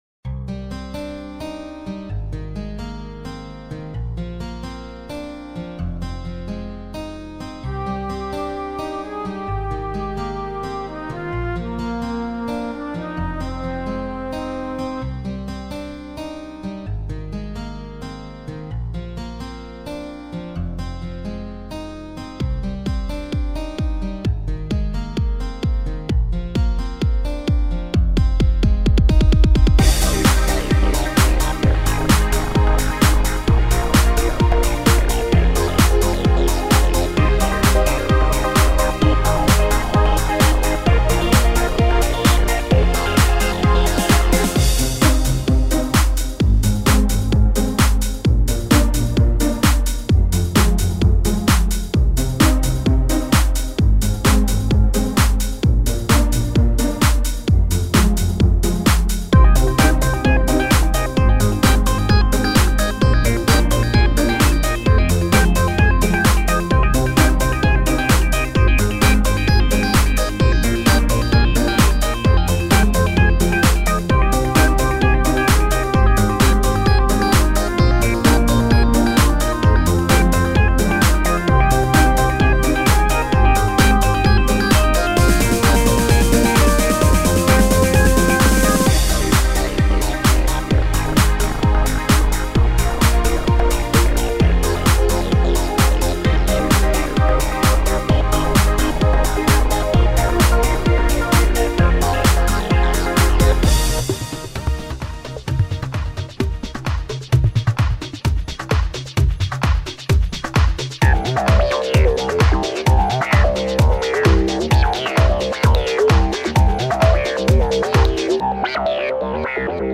dance/electronic
Two song montage, both are tempo/BPM 90